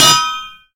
anvil_land.ogg